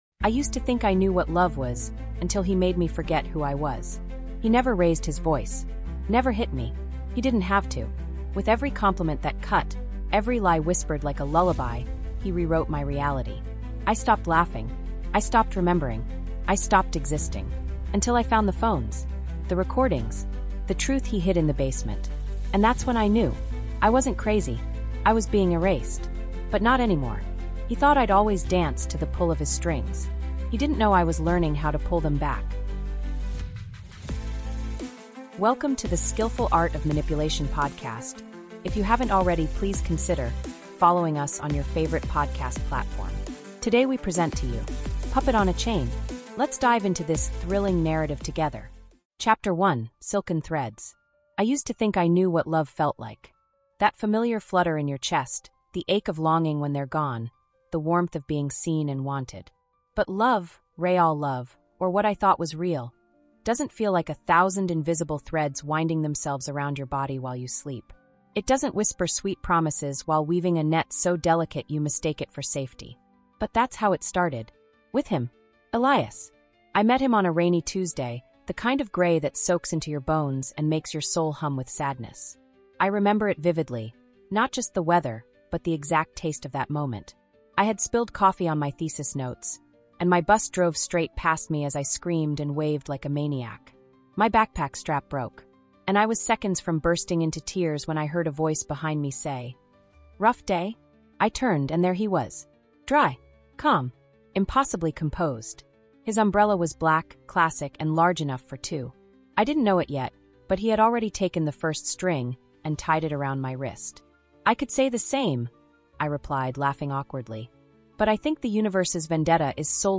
What began as warmth slowly turned into a quiet storm of psychological manipulation, where compliments cut deeper than insults and freedom was disguised as care. Told in raw, emotionally immersive first-person narration, Puppet on a Chain is a gripping 7-chapter psychological drama that takes you inside the mind of a woman being gaslit, erased, and reshaped by the man who claims to love her.